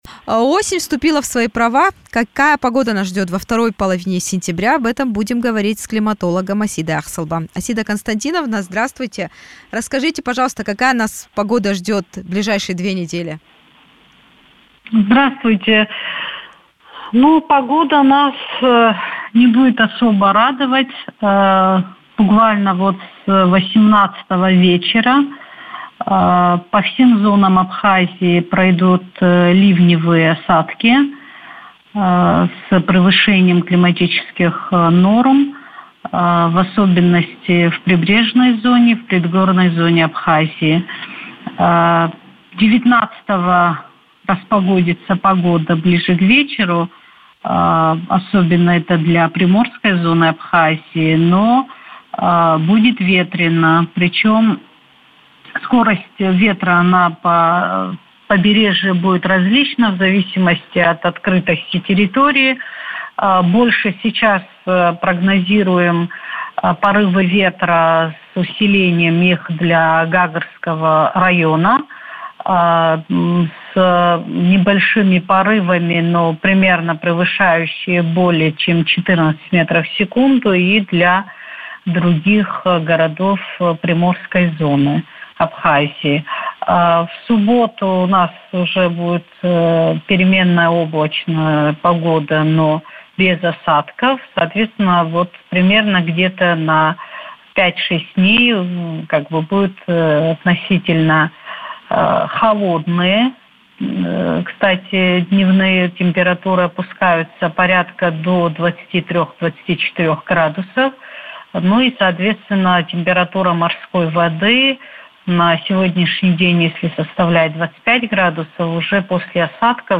климатолог